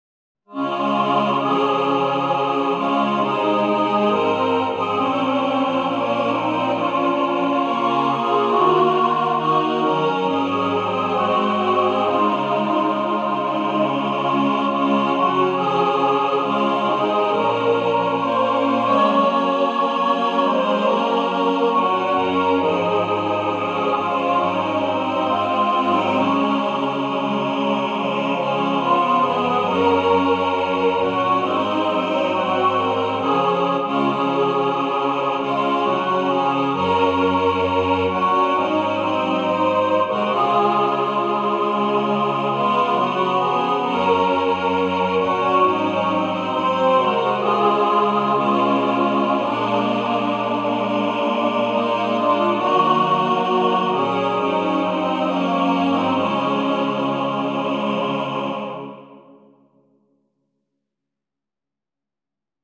Tags: hymn, sacred, Jesus, Christ, church, SATB, choir